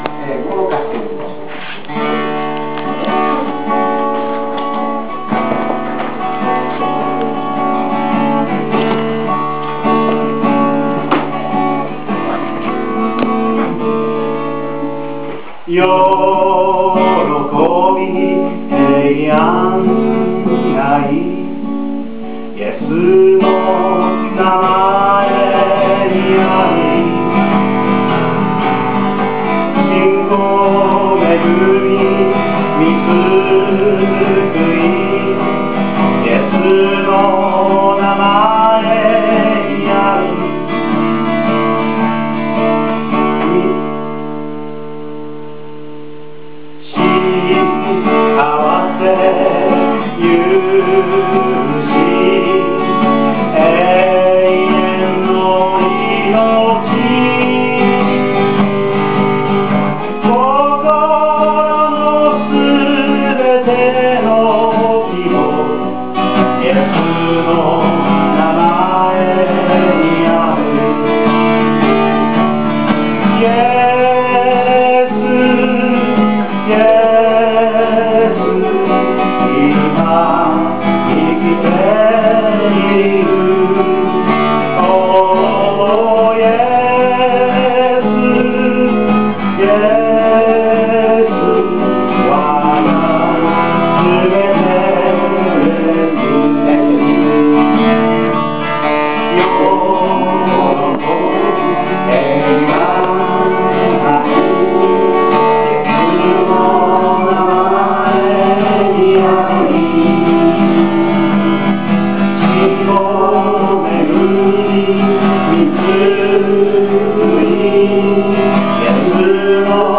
白石バプテスト教会週報